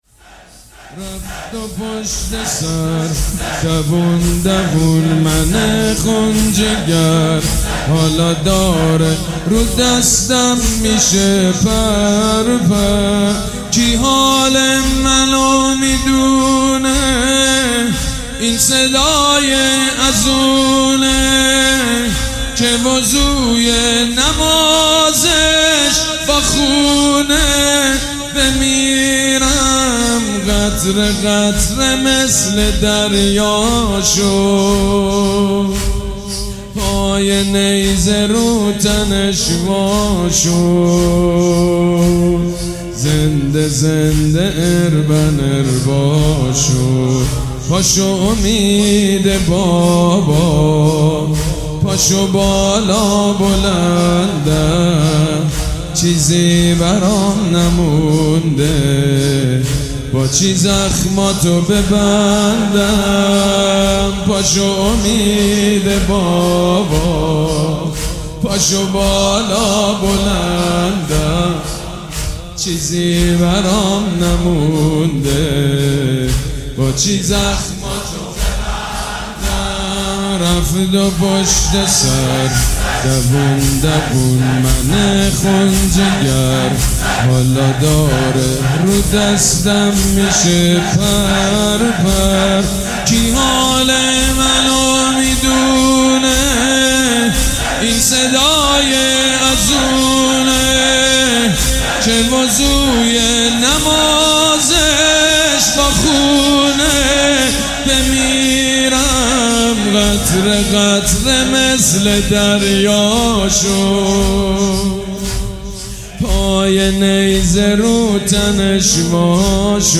مداحی شب هشتم محرم 99 سید مجید بنی فاطمه